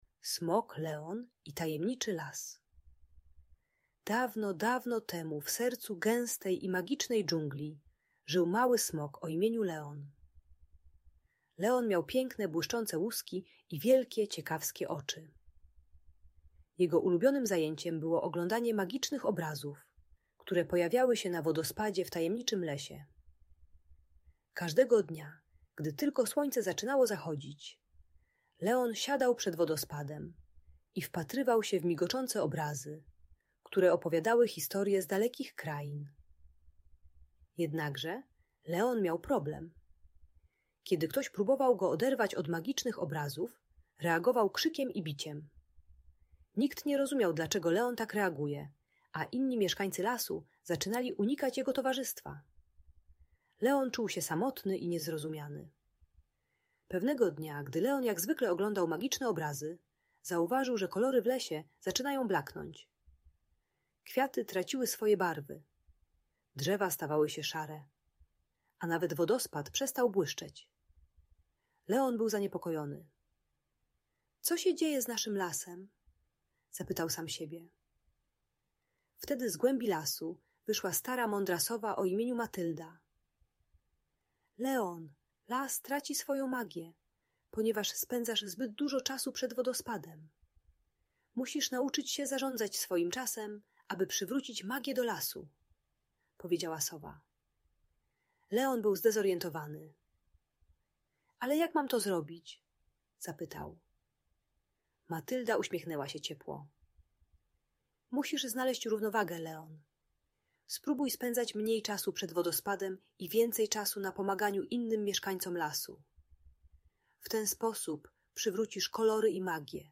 Smok Leon i Tajemniczy Las - Audiobajka